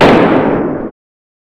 and now a sniper Very Happy
Erm, I have to say, the only really usable one is gun burst 3, but all of them, it included, are too echoy and distant, if you get what I'm saying.
sniper_211.wav